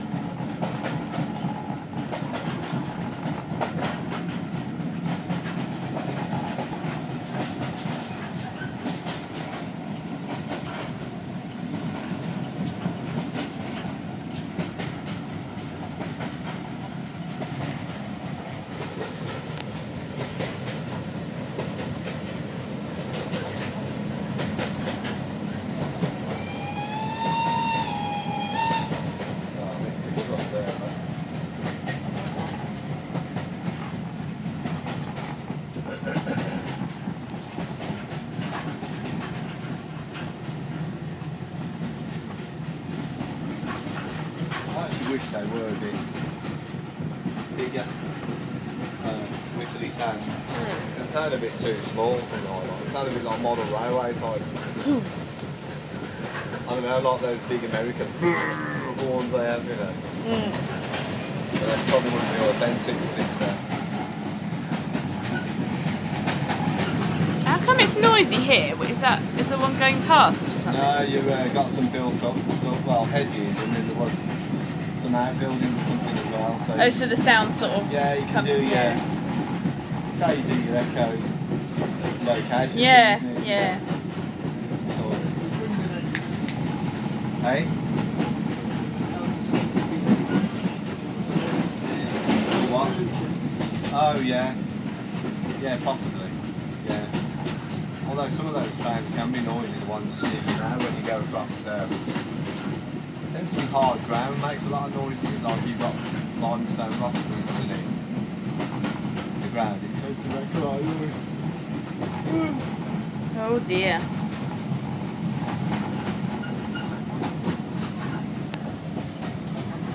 Lots of whistles and big sneeze